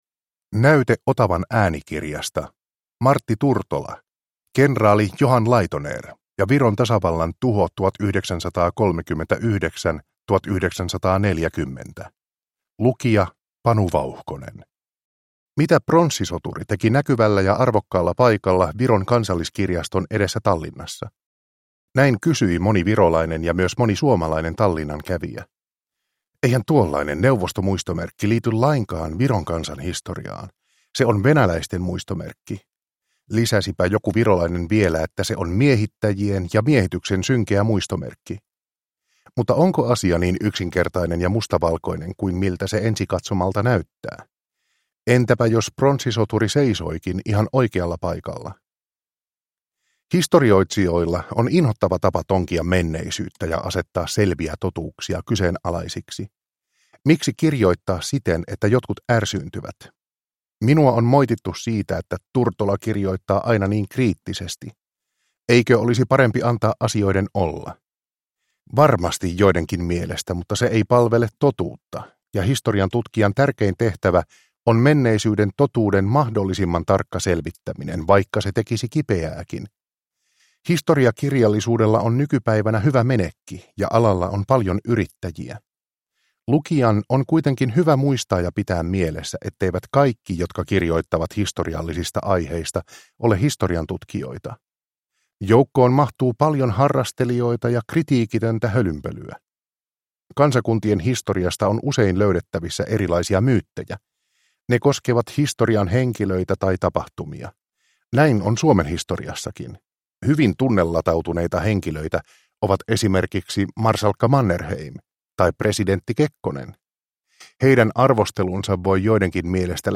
Kenraali Johan Laidoner ja Viron tasavallan tuho 1939-1940 – Ljudbok – Laddas ner